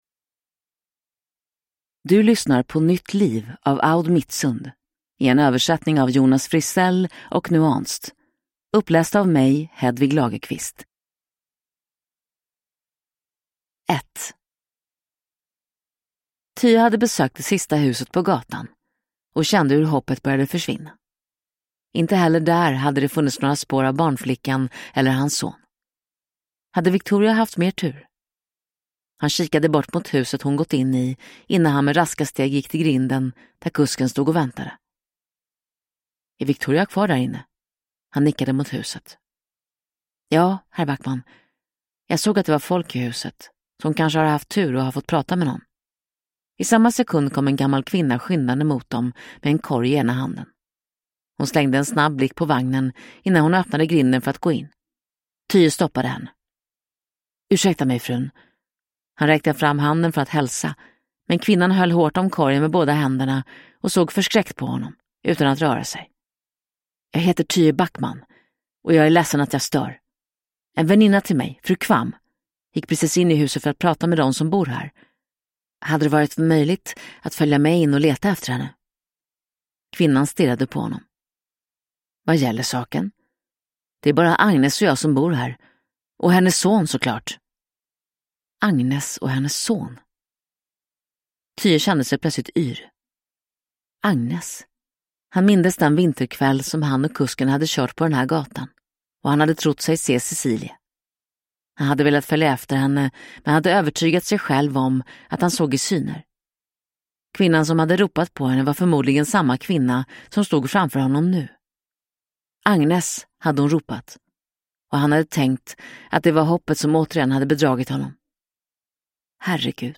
Nytt liv – Ljudbok